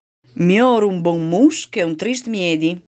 File audio in dialetto dei proverbi in fondo in pdf